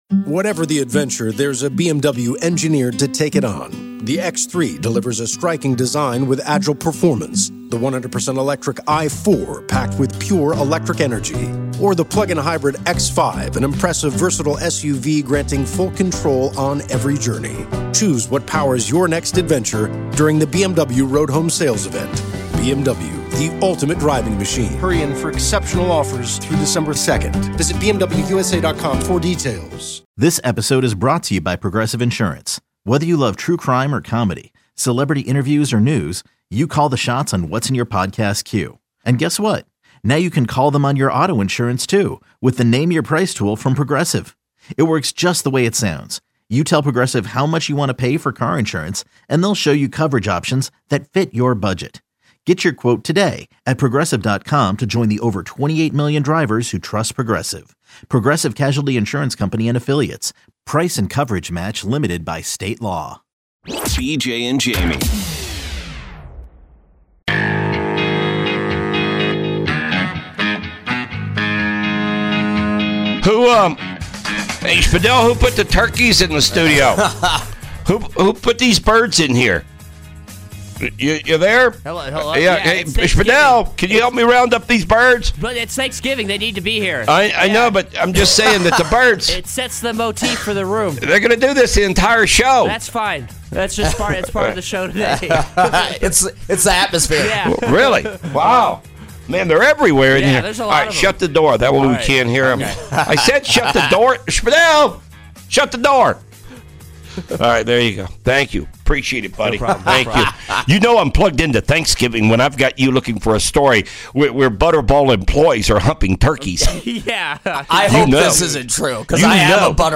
Irreverent, funny, and real-life radio